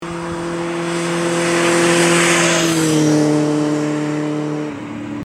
- fichier audio de la moto à exploter avec Audacity